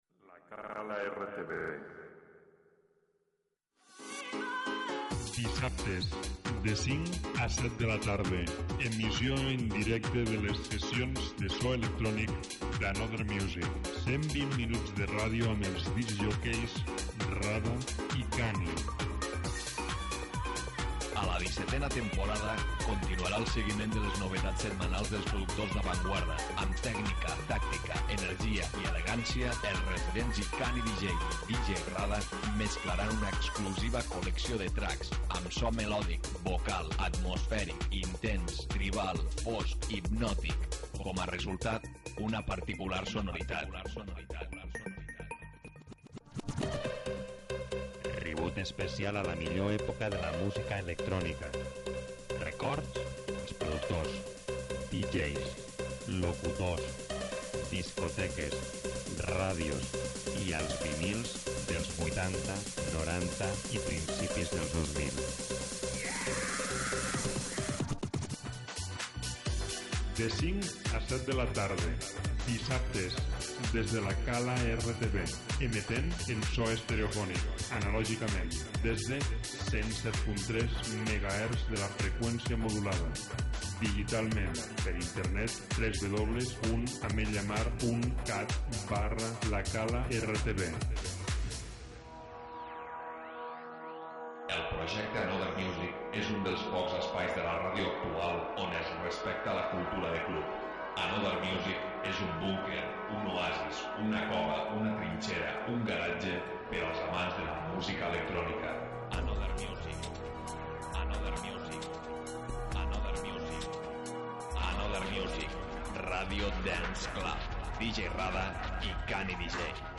en directe el dissabte 06/05/17.